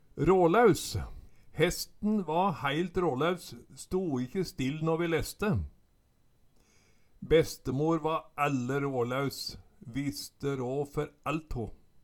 Høyr på uttala Ordklasse: Adjektiv Attende til søk